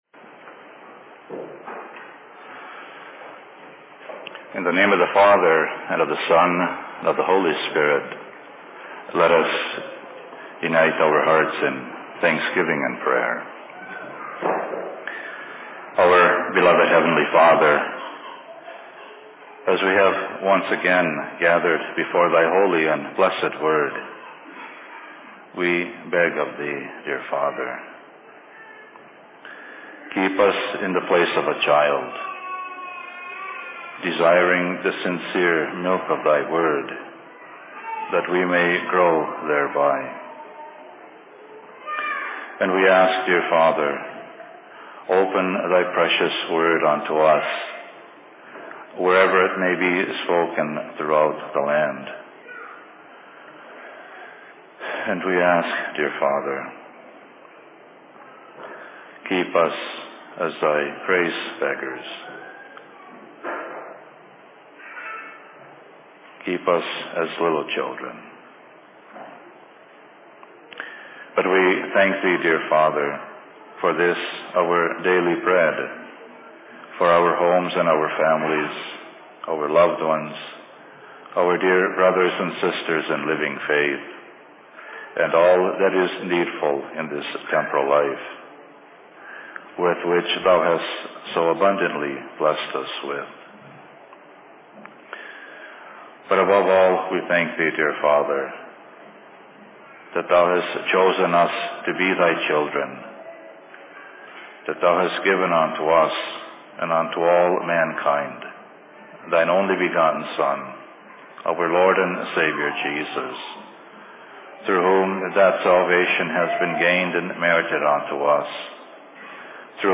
Sermon on Minneapolis 24.01.2010
Location: LLC Minneapolis